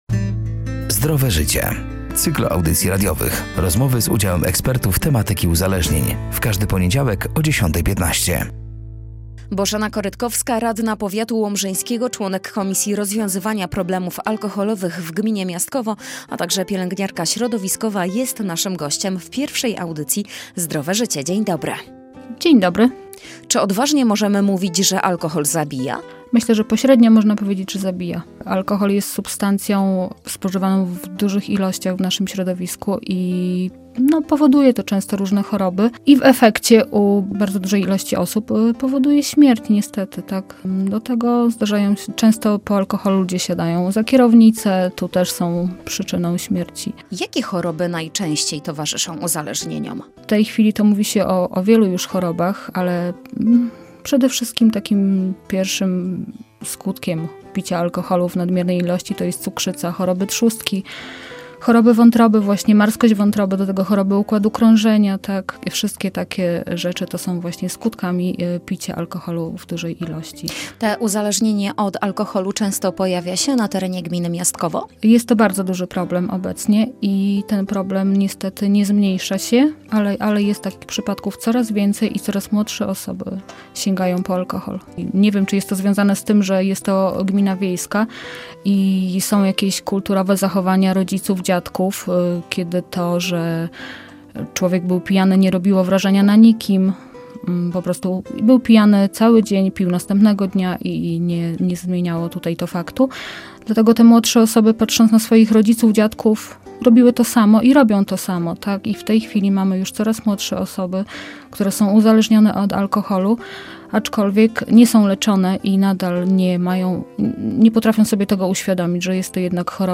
„Zdrowe Życie” to cykl audycji radiowych. Rozmowy z udziałem ekspertów tematyki uzależnień.
Gościem pierwszej audycji była Bożena Korytkowska, radna powiatu łomżyńskiego, członek Komisji Rozwiązywania Problemów Alkoholowych w Gminie Miastkowo, a także pielęgniarka środowiskowa.